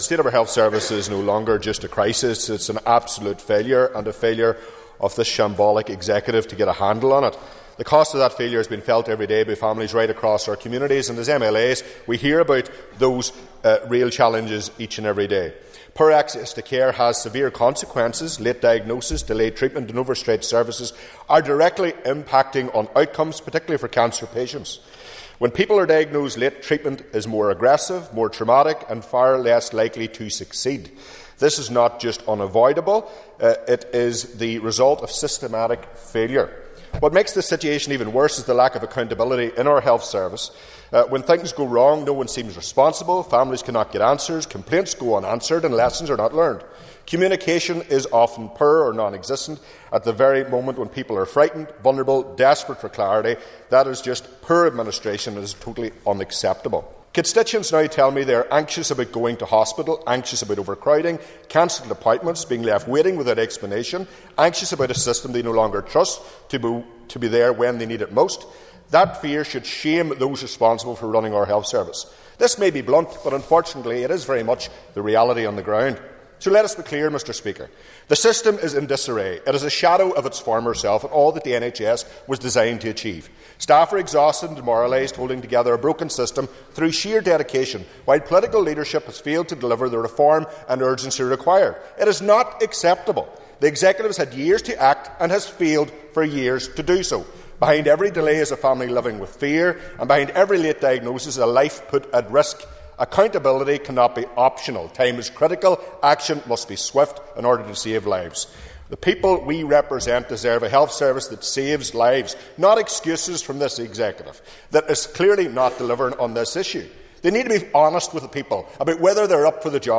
West Tyrone MLA Daniel McCrossan told the Assembly that long waiting lists, late diagnoses, delayed access to services and numerous other failings have become the hallmark of what was once a great health service, but is now broken.